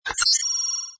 ui_primary_window_open.wav